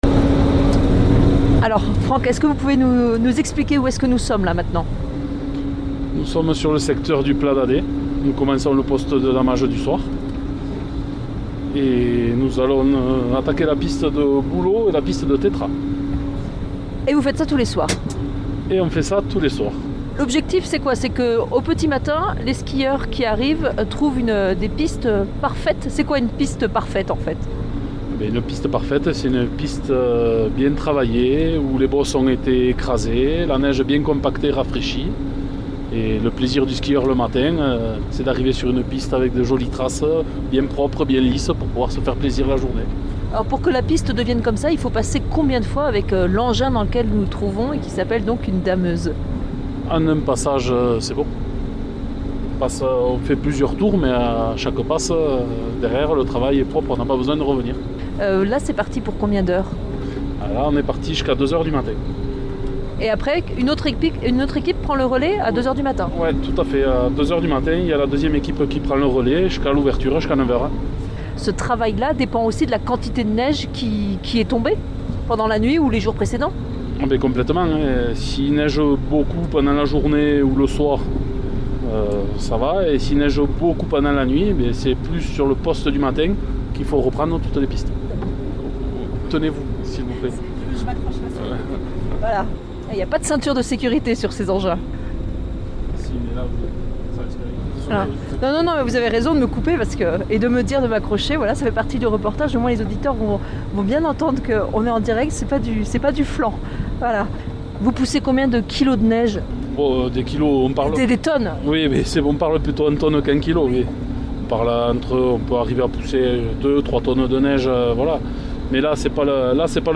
Reportage en dameuse à Saint-Lary